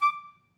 DCClar_stac_D5_v2_rr1_sum.wav